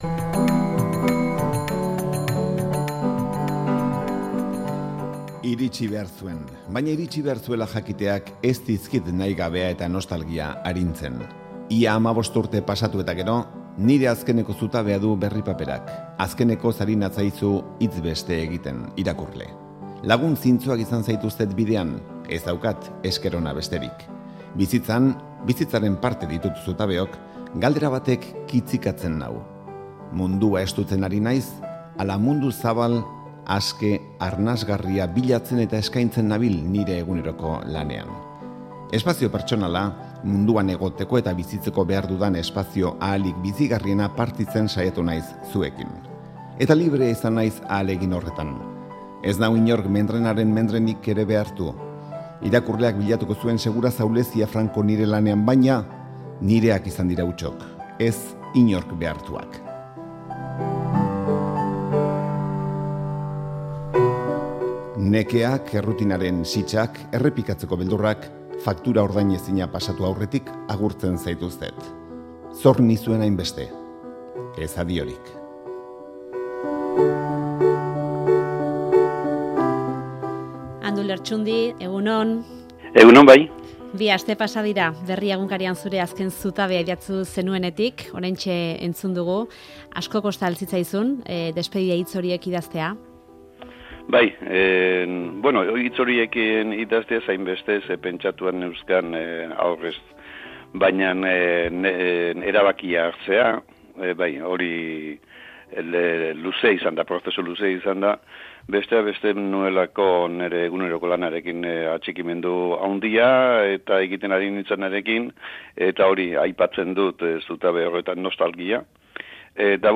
Bi aste badira eginkizun hau utzi zuela. Amaraunean hitzegin digu zutabeaz eta etorkizunaz